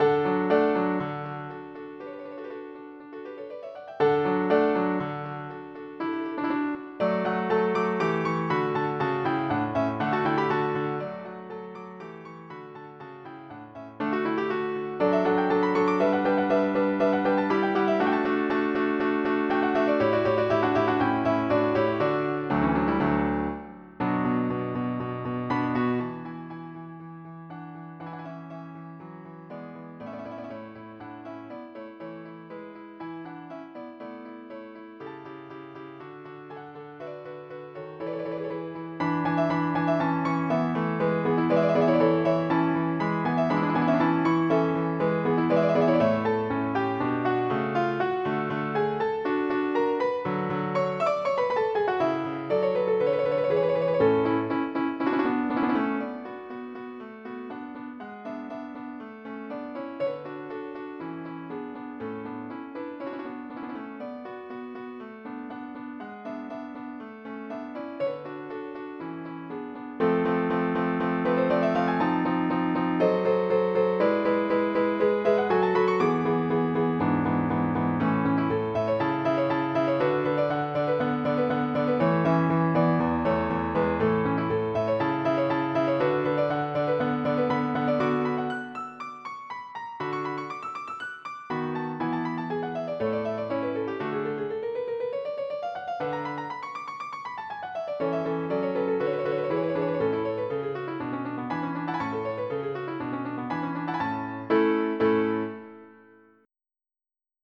MIDI Music File
General MIDI (type 1)
Mozart Quartet-MIDI File.mp3